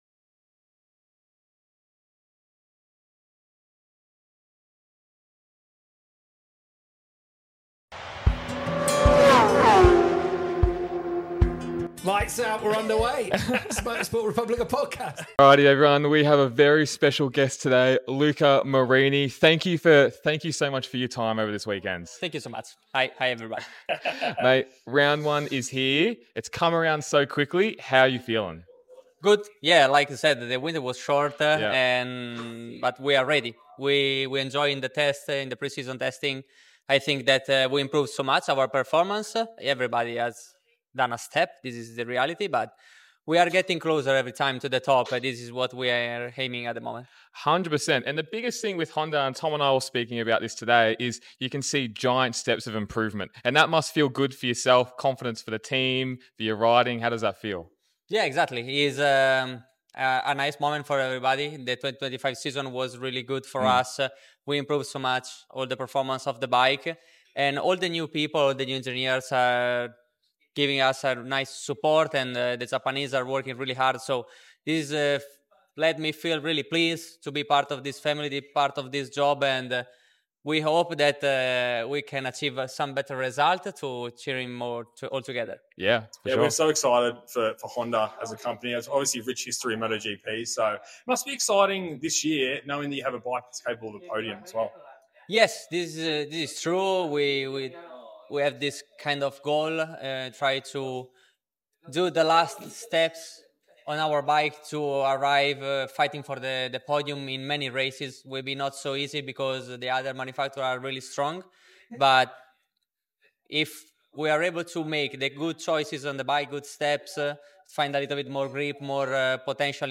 Disclaimer: THIS VIDEO WAS FILMED OUTSIDE THE CIRCUIT AT OUR HOTEL.